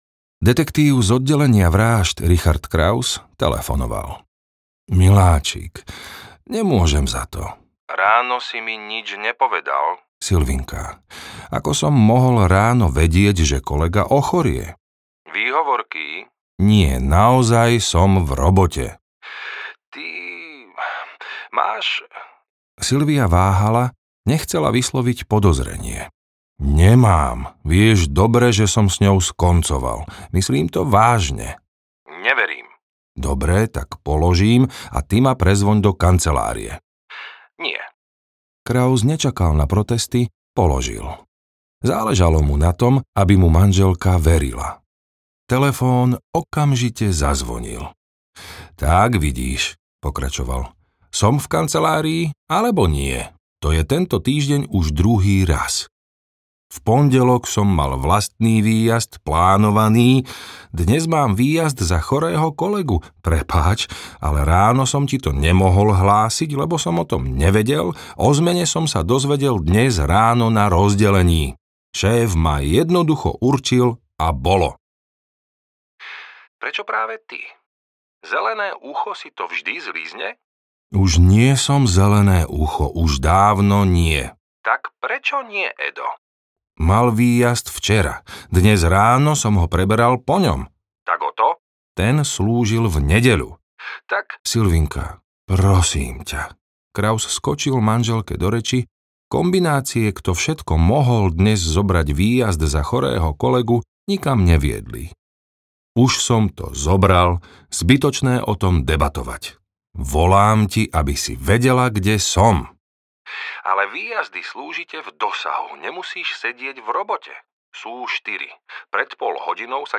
Tajomný závoj audiokniha
Ukázka z knihy